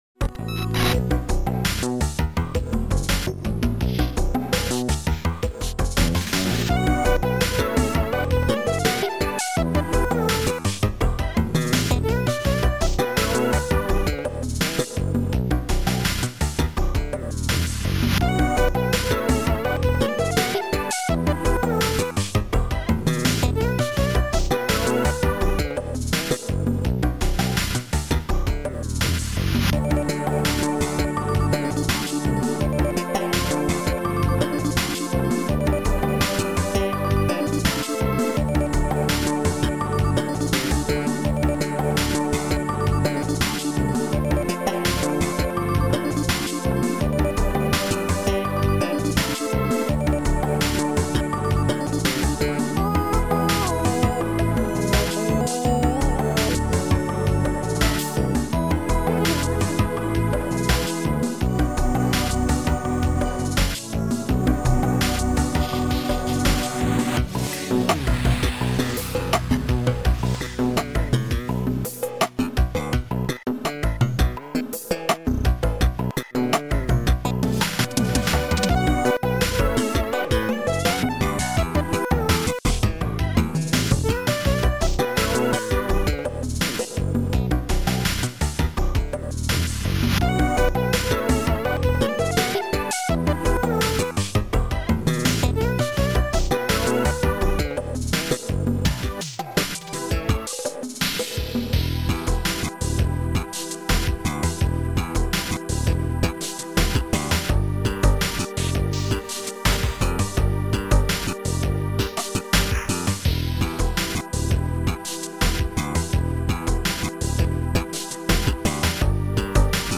Sound Format: Noisetracker/Protracker
Sound Style: Funky Synth Pop